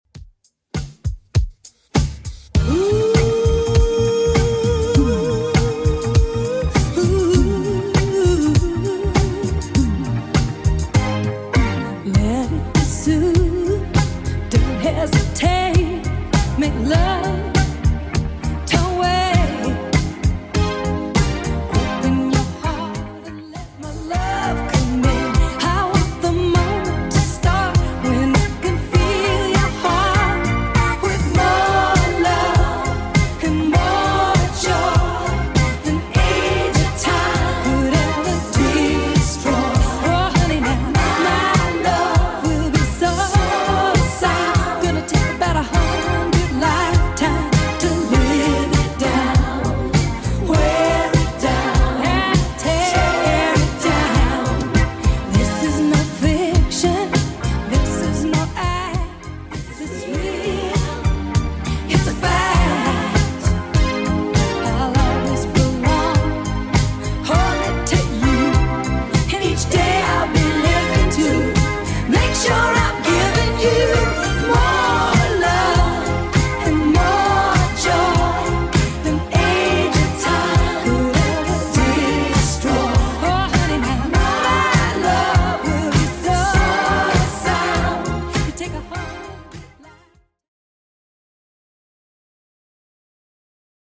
Genre: 90's
BPM: 102